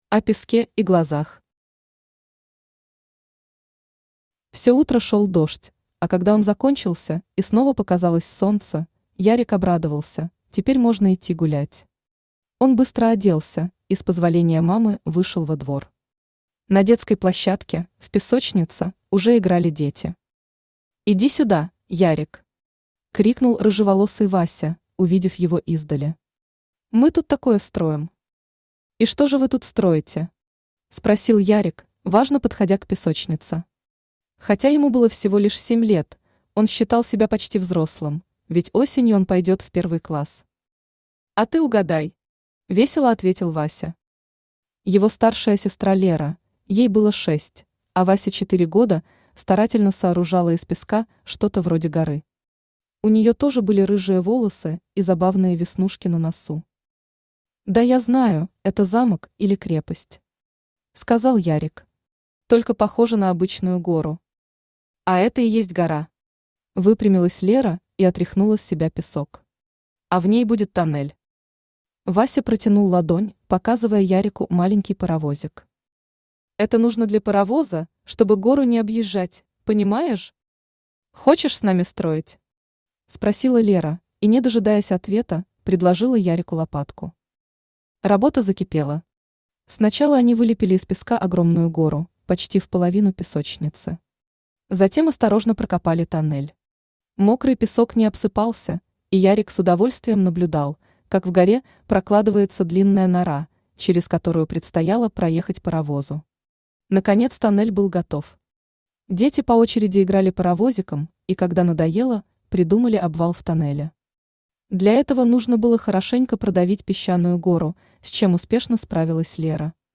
Aудиокнига Чтобы не было беды Автор Ольга Николаевна Новикова Читает аудиокнигу Авточтец ЛитРес. Прослушать и бесплатно скачать фрагмент аудиокниги